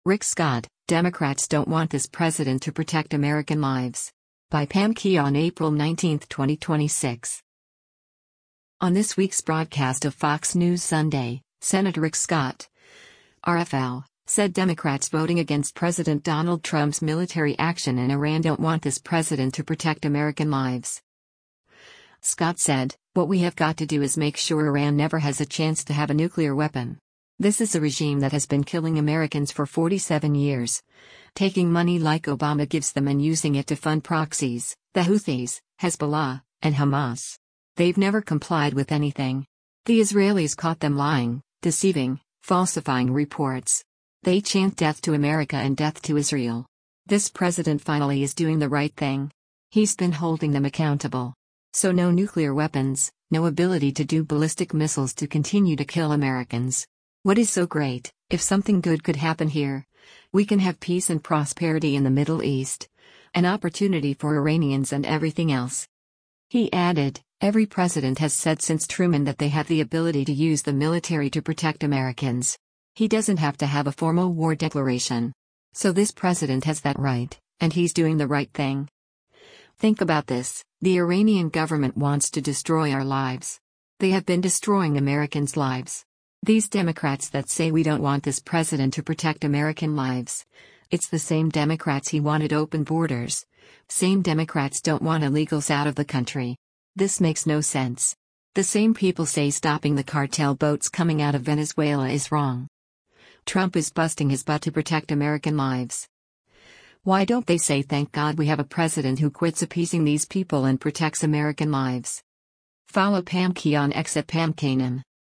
On this week’s broadcast of “Fox News Sunday,” Sen. Rick Scott (R-FL) said Democrats voting against President Donald Trump’s military action in Iran “don’t want this president to protect American lives.”